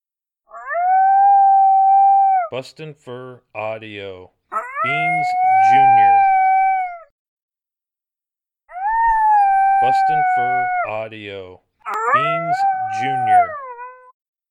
BFA’s Beans in her younger years lone howling, excellent stand starter howl.
• Product Code: howls